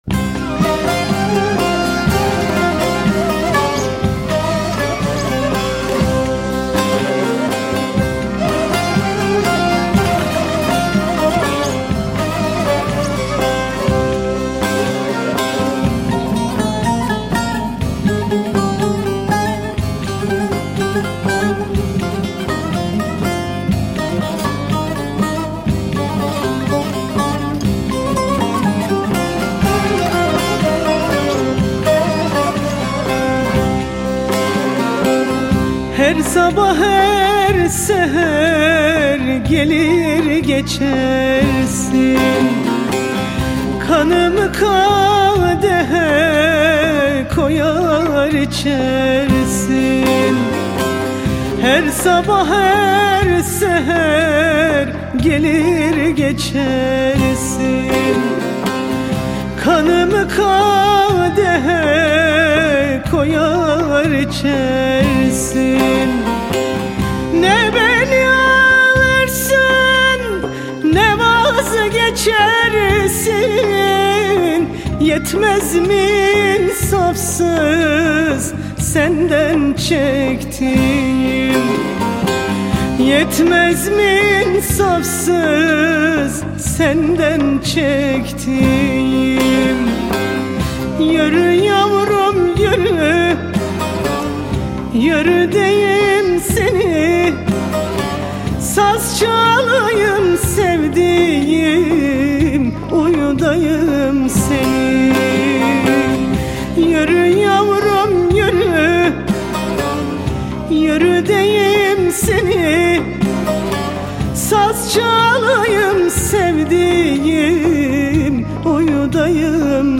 Etiketler: ankara, türkü, müzik, türkiye